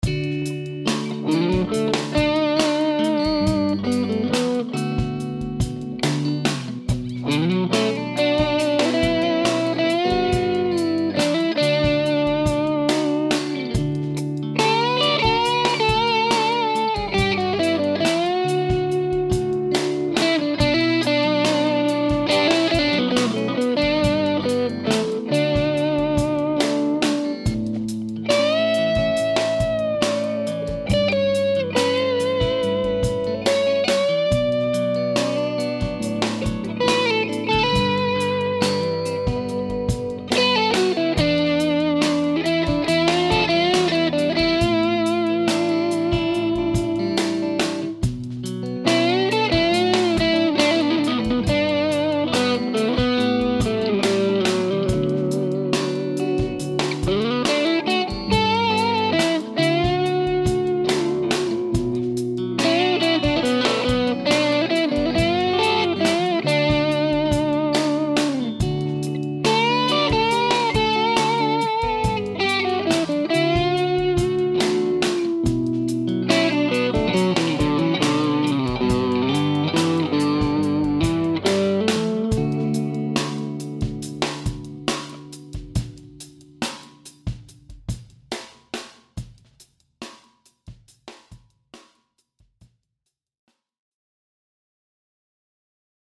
So I decided to use my little Fender Champ 600 and my 1 X 12 cab (because the Champ just doesn’t have enough bottom-end).
So my solution to get some grind? I took the dust off my old TS-808 Tube Screamer, threw it in my gig bag, grabbed my Tele, and went to church!